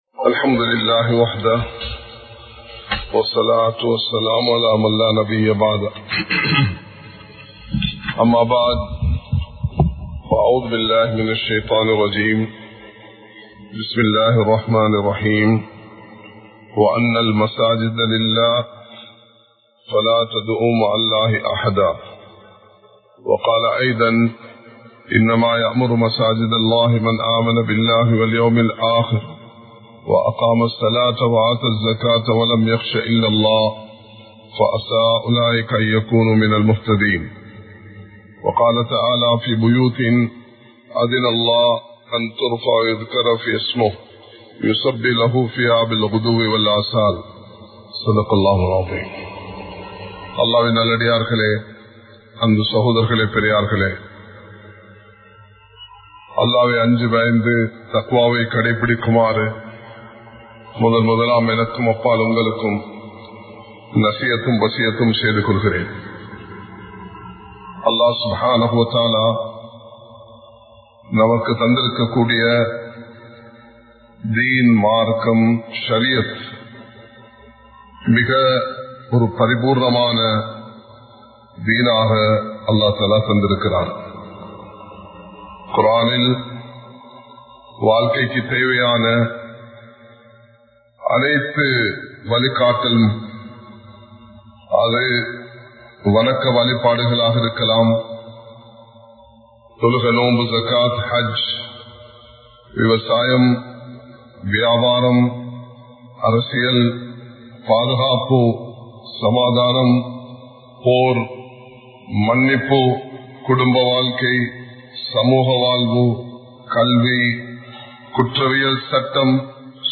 பொறுப்புகளை நிறைவேற்றுவோம் | Audio Bayans | All Ceylon Muslim Youth Community | Addalaichenai
Kollupitty Jumua Masjith